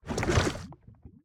step_lava5.ogg